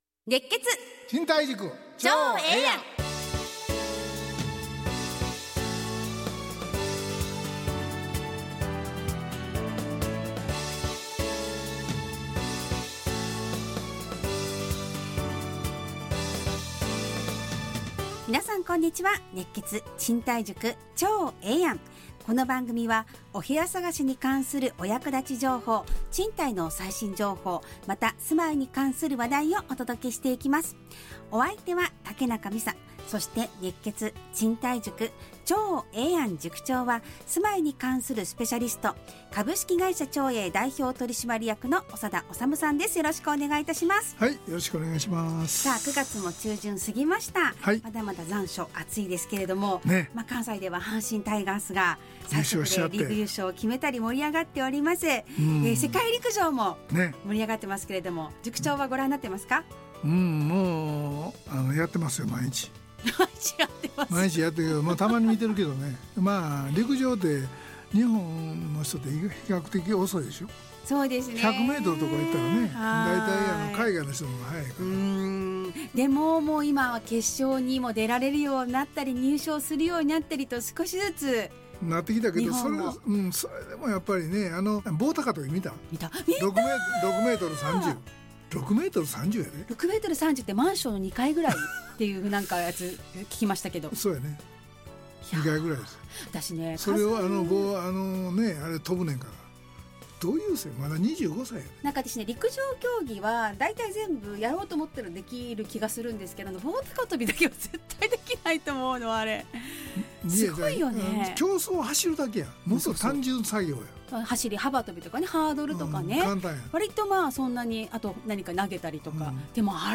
ラジオ放送 2025-09-22 熱血！